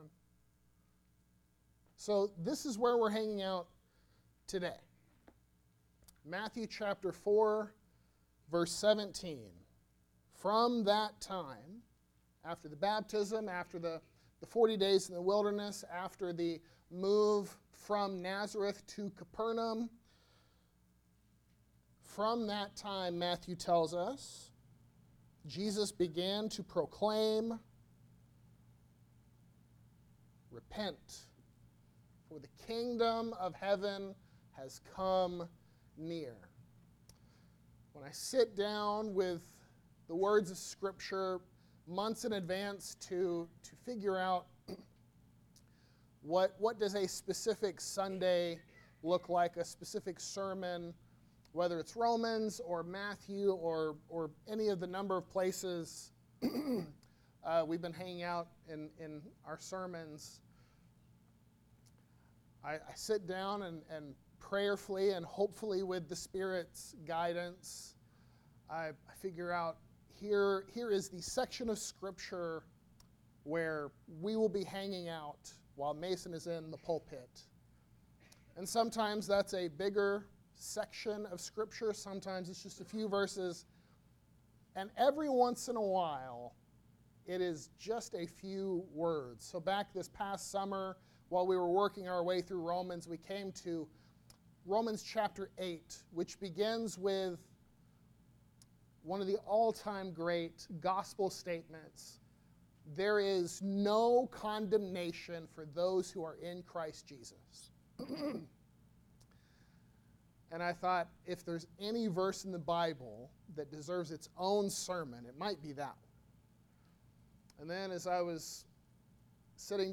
The Only Sermon